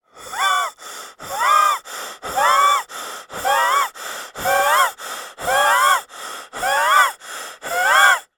На этой странице собраны звуки хрипов разного характера: сухие и влажные, свистящие и жужжащие.
Звук человека, задыхающегося от астмы